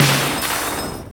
SI2 SPLASH 2.wav